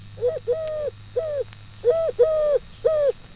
TortoraDalCollare.wav